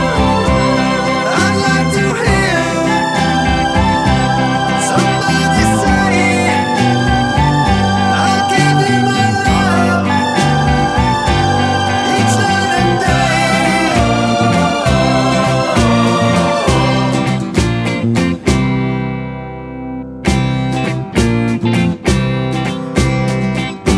Down 3 Semitones Pop (1950s) 2:25 Buy £1.50